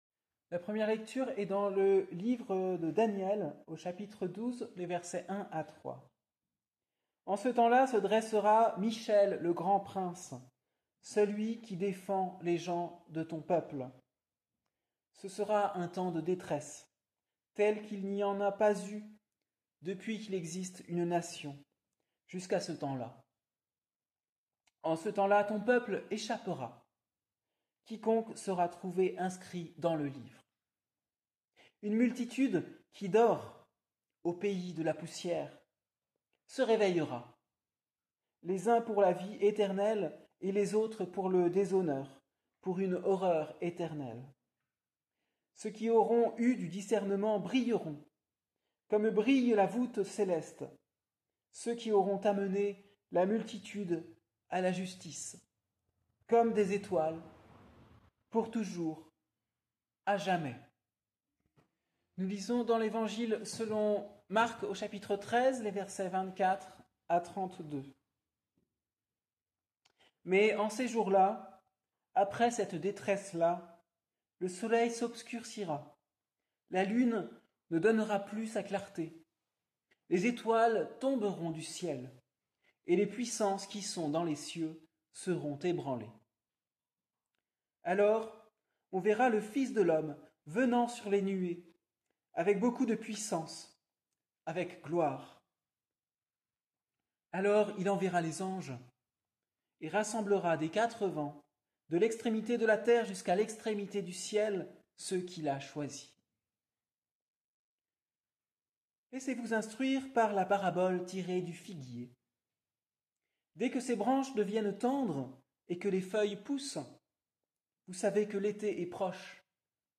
Prédication du dimanche 17 novembre 2024.
1. Textes bibliques et prédication